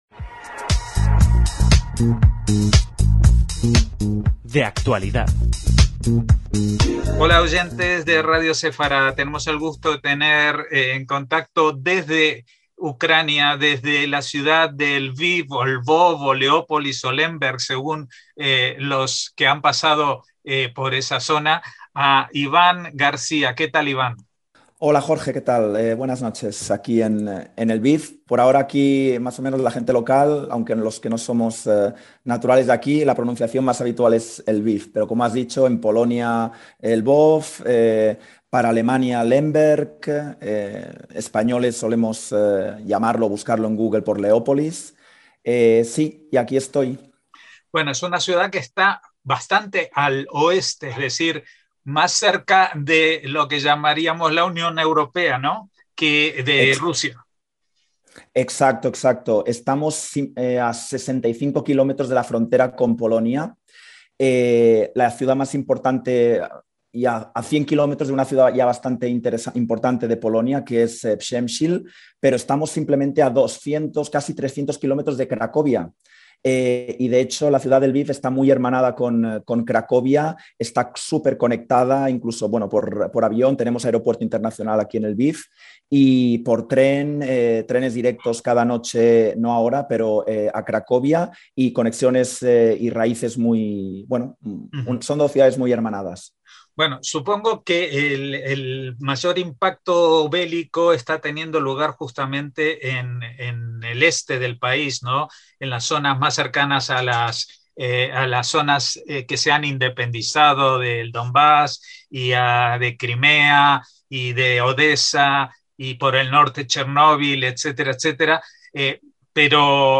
En directo desde Ucrania